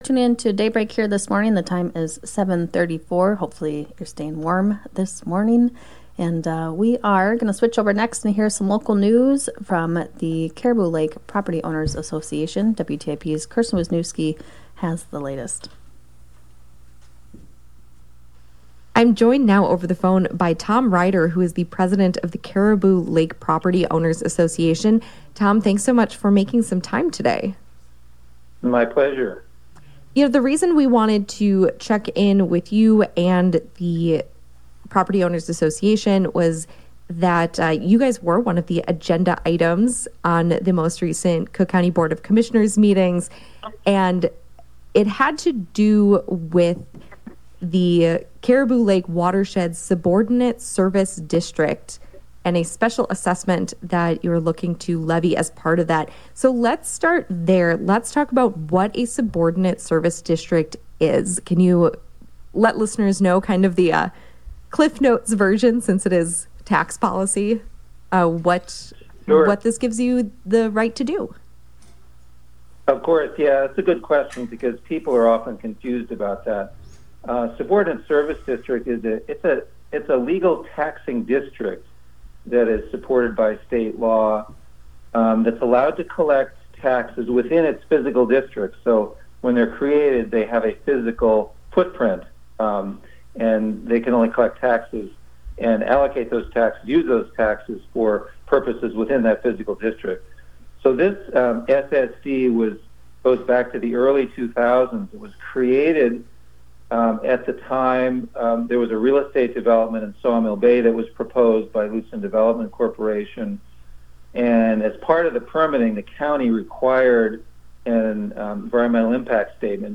Current News and Events WTIP Interview Regarding the Caribou Lake Subordinate Service District renewal and public hearing (click to listen or) Download County Assessor Presentation (Click to View or) Download
WTIP-Interview-Regarding-the-Caribou-Lake-SSD.mp3